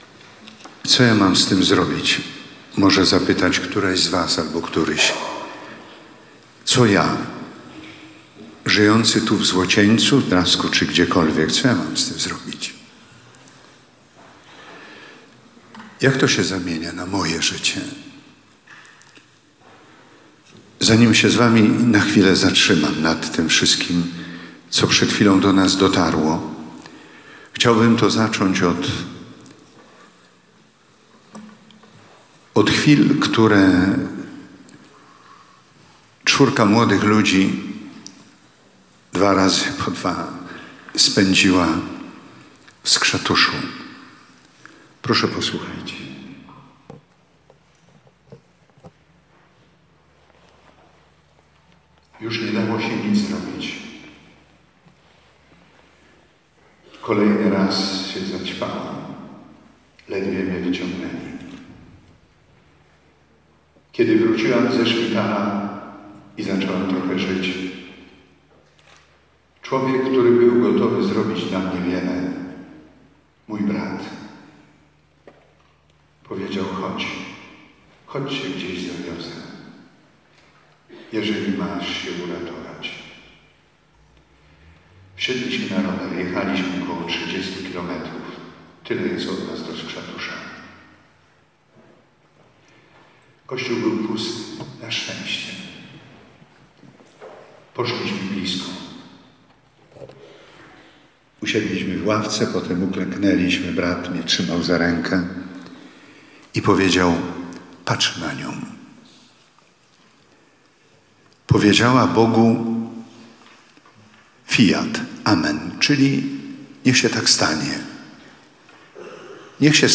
Zapraszamy do wysłuchania konferencji wygłoszonej przez bp. Edwarda Dajczaka podczas Adwentowych Czuwań Młodych.
Pierwsze czuwanie odbyło się 2 grudnia w kościele pw. Wniebowzięcia NMP w Złocieńcu.